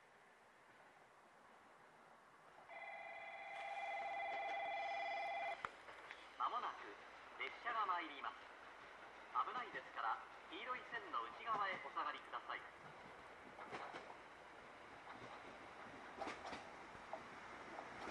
この駅では接近放送が設置されています。
１番のりば日豊本線
接近放送普通　宮崎行き接近放送です。